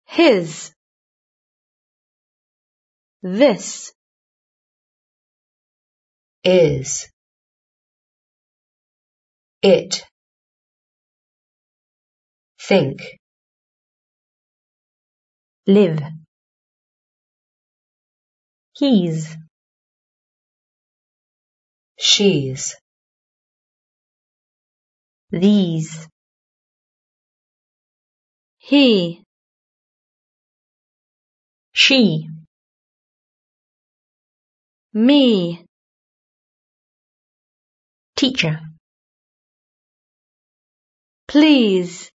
Now listen again and repeat the words.